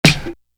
Metro Snap.wav